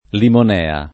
limonea [ limon $ a ] s. f.